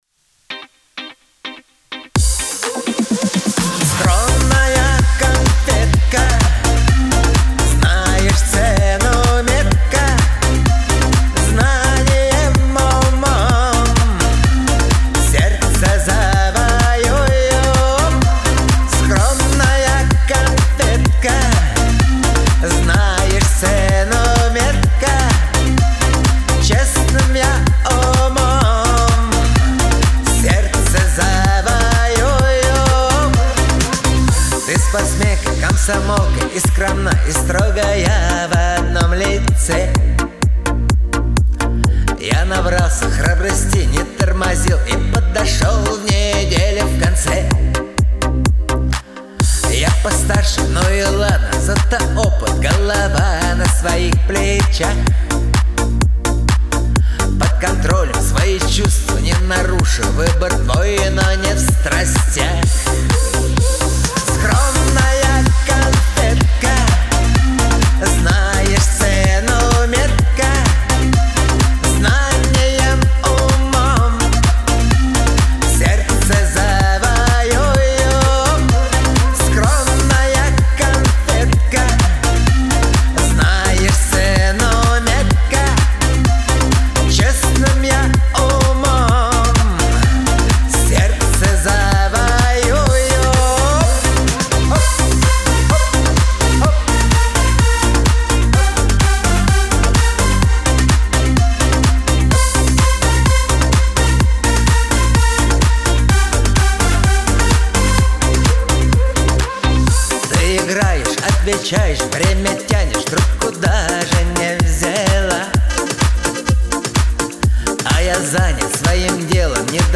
Трек размещён в разделе Рэп и хип-хоп / Русские песни / Поп.